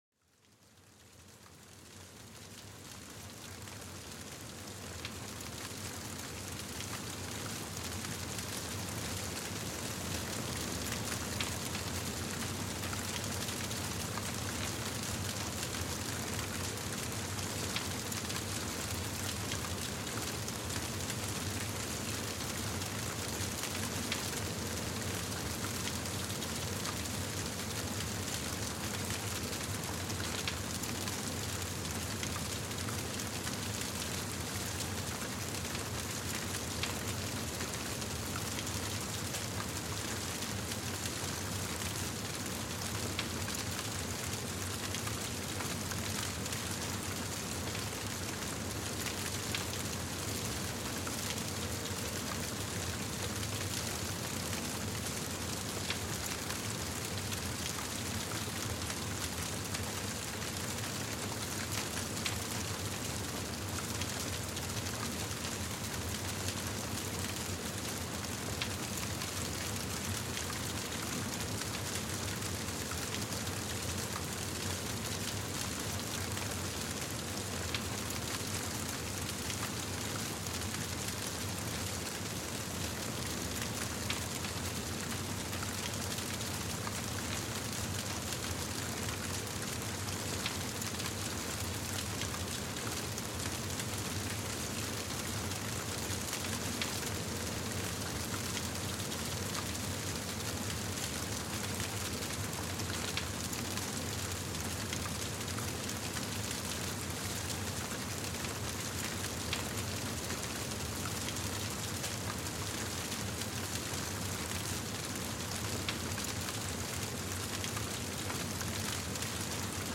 Crépitement du feu : Plongée dans une détente chaleureuse
Laissez-vous envelopper par le doux crépitement d’un feu de cheminée, un son apaisant qui évoque chaleur et réconfort. Chaque crépitement vous transporte dans une ambiance cocooning parfaite pour calmer l’esprit.